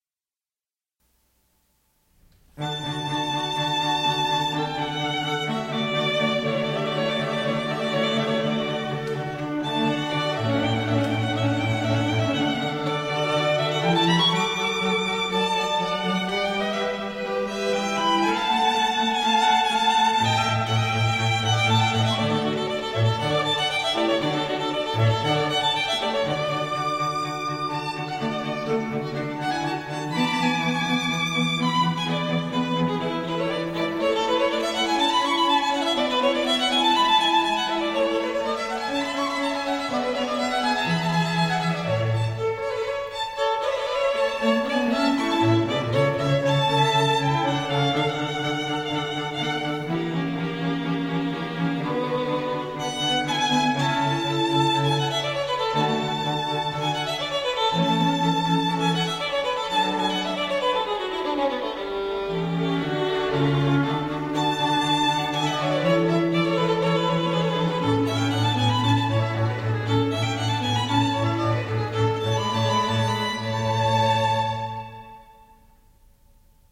ELEGANT STRING MUSIC FOR ANY OCCASION